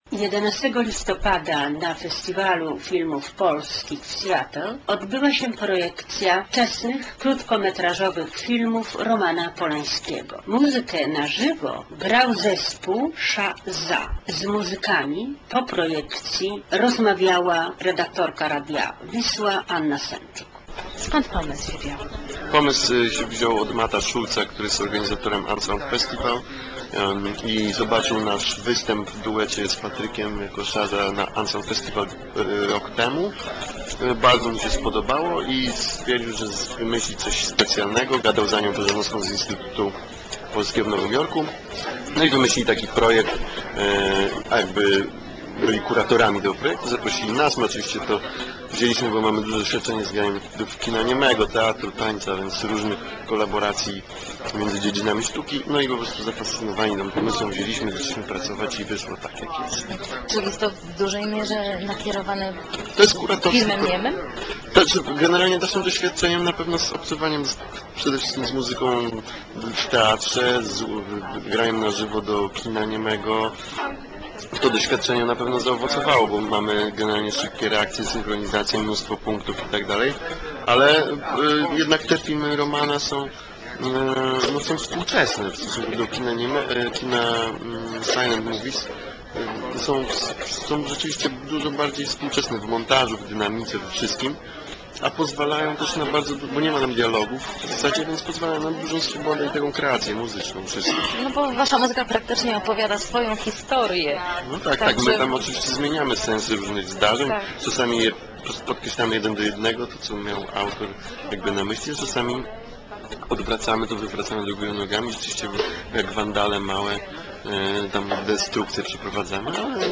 fragmenty muzyczne -- na zywo-- grupa Sza/Za.